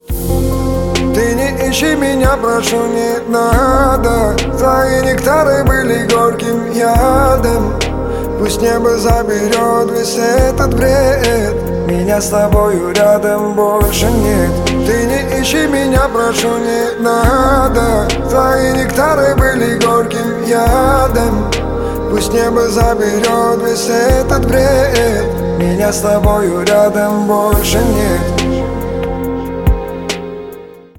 лирика
спокойные